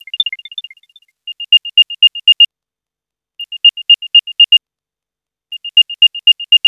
Index of /media/EM_Pickup
Airtag_Beep.flac